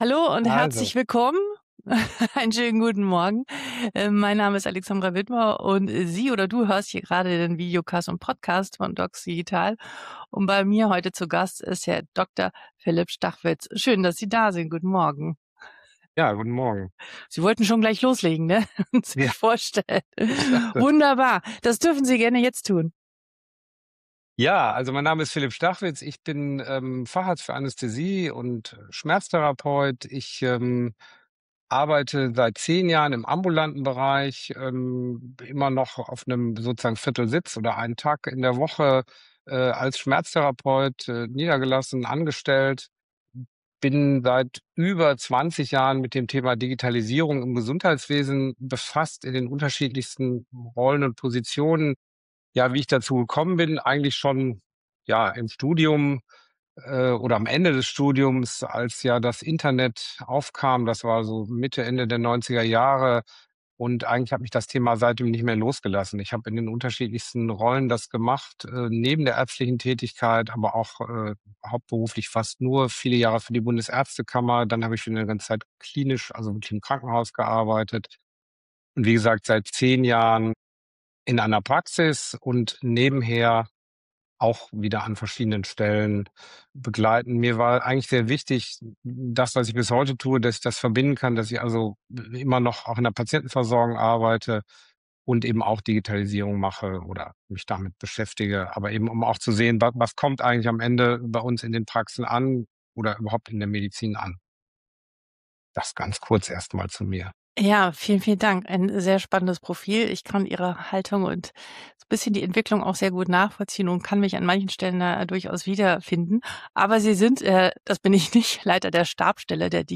Im Gespräch geht es um E-Rezept, elektronische Patientenakte und künstliche Intelligenz. Und um die zentrale Frage, warum viele digitale Lösungen in der Theorie gut klingen, im Praxisalltag aber scheitern. Eine ehrliche Diskussion über Praxissoftware, ärztliche Realität und die Zukunft der Versorgung.